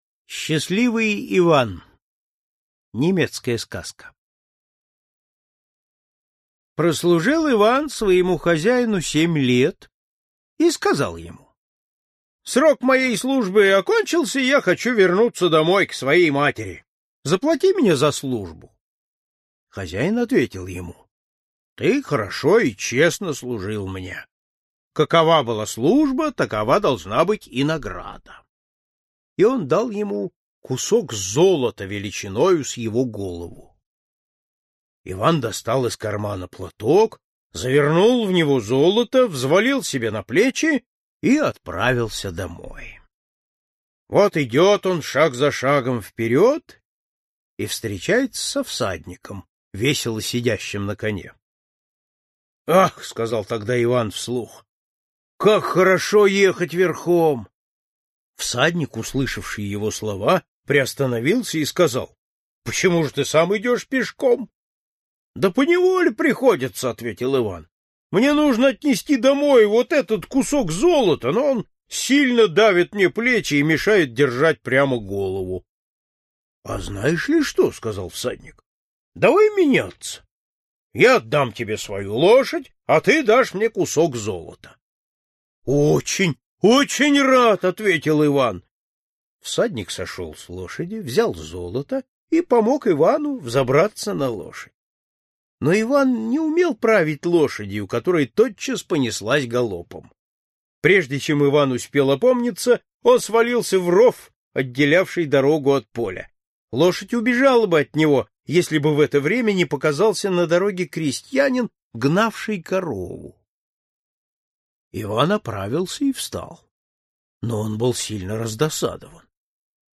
Аудиокнига Золотая книга сказок. Немецкие сказки | Библиотека аудиокниг